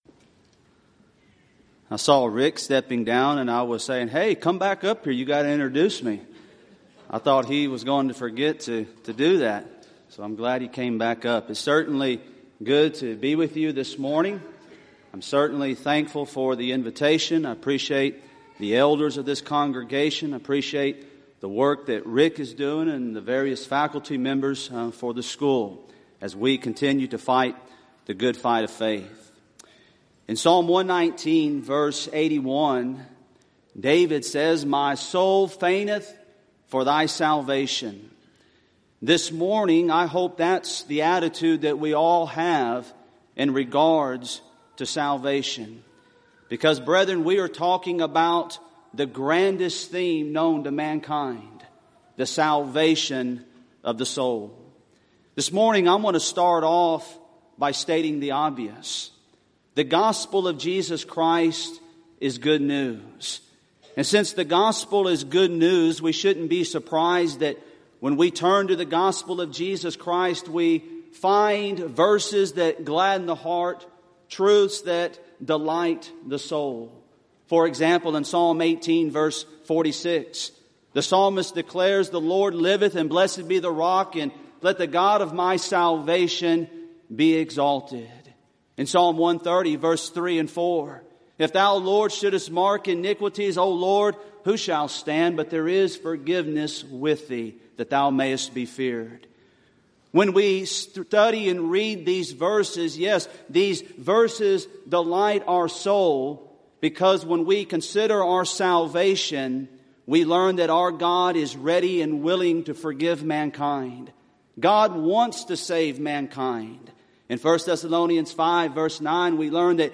Event: 29th Annual Southwest Lectures Theme/Title: Proclaiming Christ: Called Unto Salvation
lecture